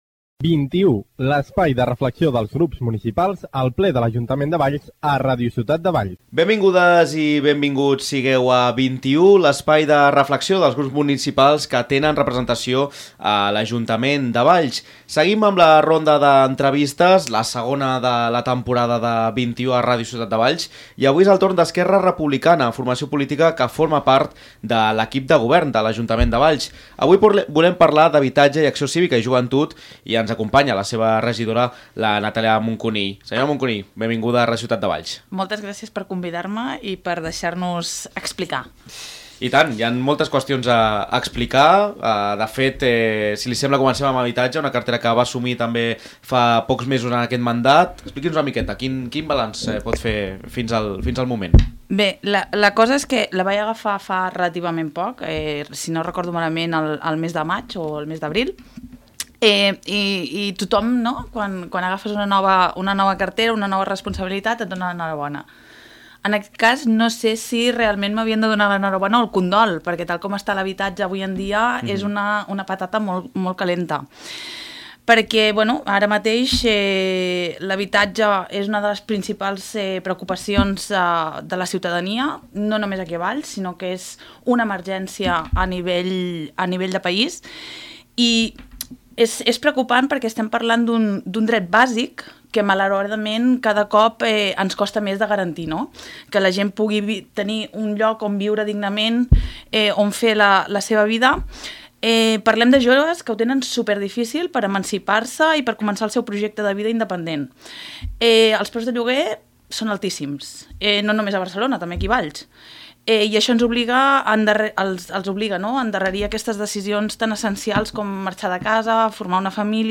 Avui és el torn d’ERC i parlem amb Natàlia Moncunill, regidora d’Habitatge, Acció Cívica i Joventut.